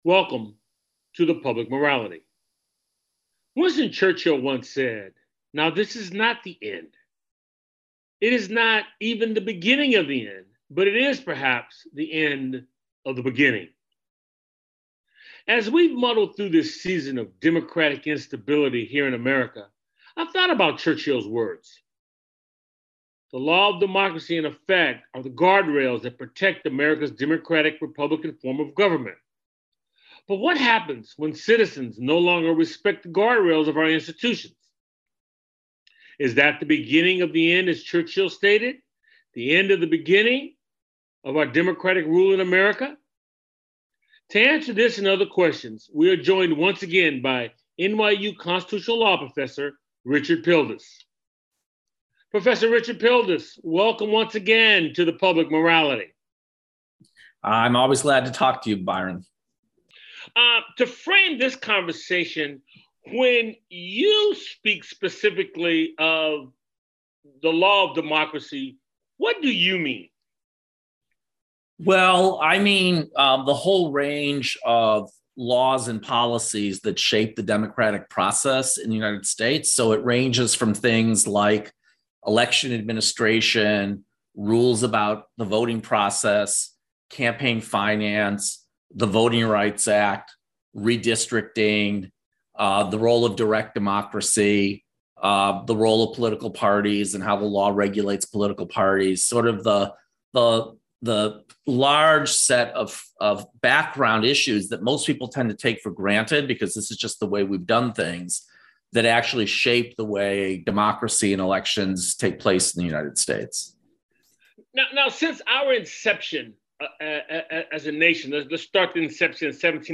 The show airs on 90.5FM WSNC and through our Website streaming Tuesdays at 7:00p.